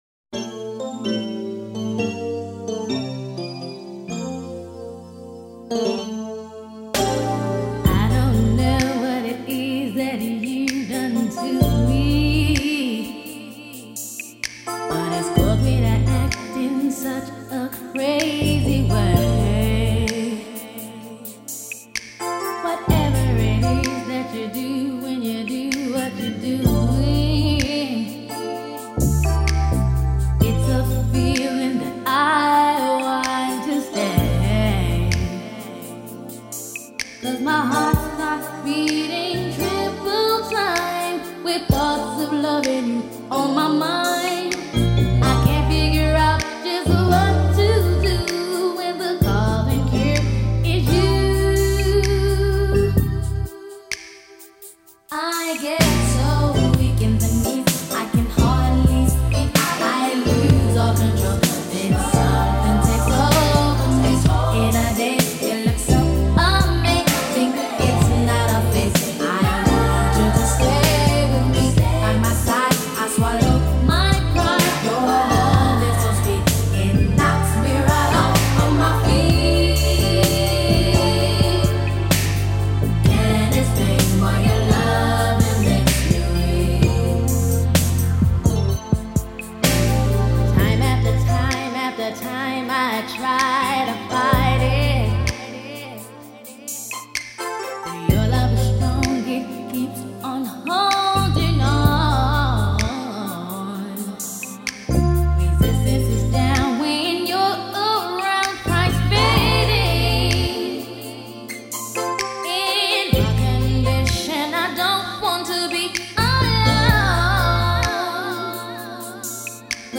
но популярность им принесли баллады в стиле ритм-н-блюз.